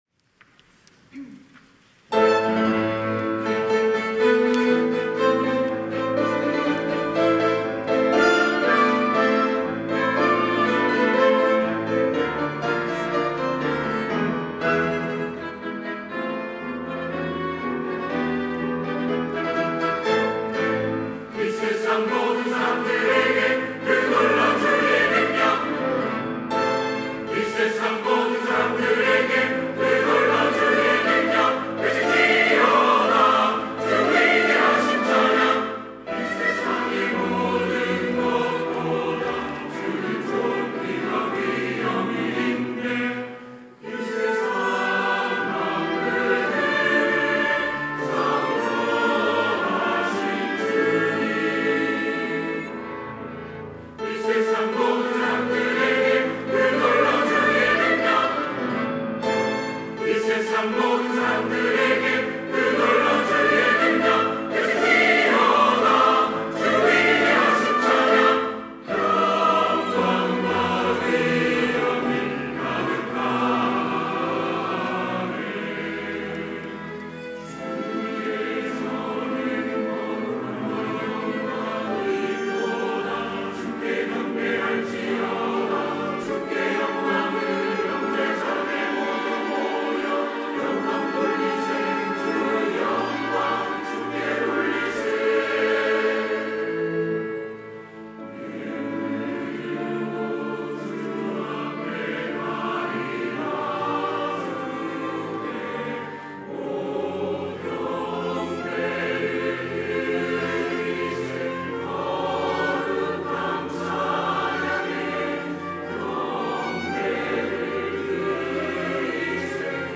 찬양대 호산나